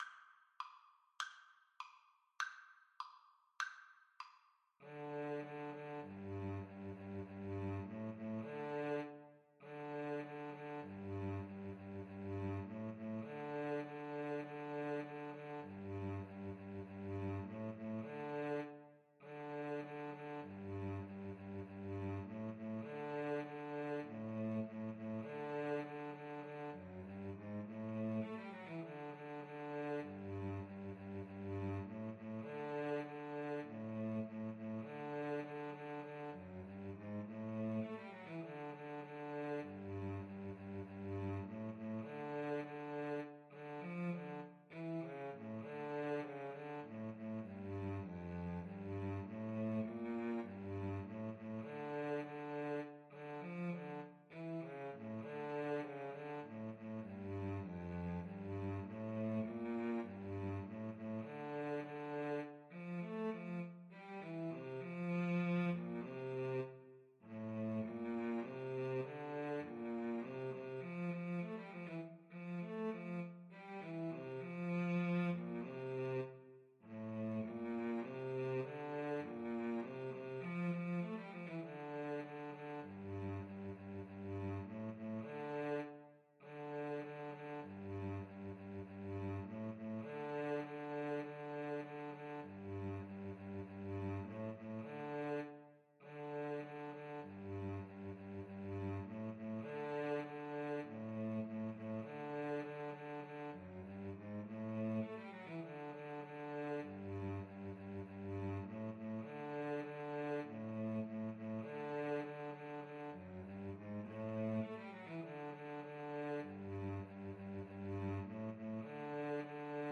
2/4 (View more 2/4 Music)
Cello Duet  (View more Intermediate Cello Duet Music)
Classical (View more Classical Cello Duet Music)